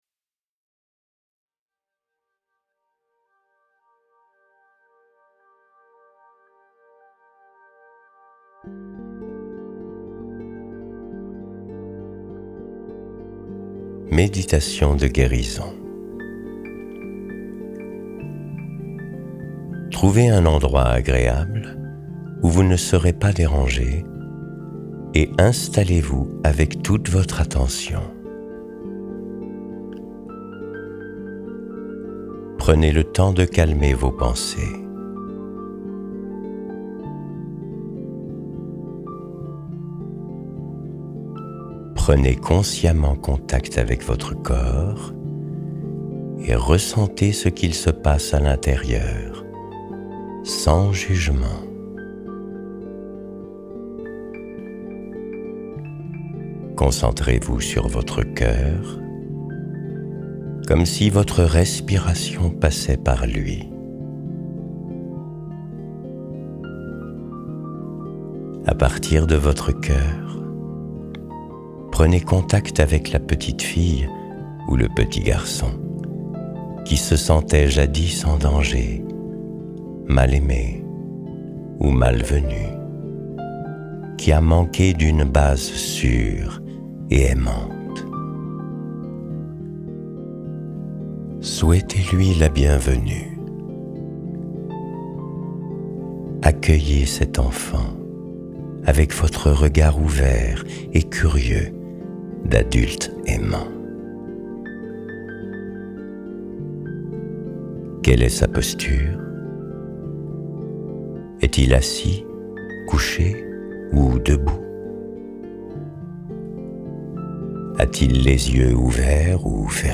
Méditation de guérison
méditation-de-guérison.mp3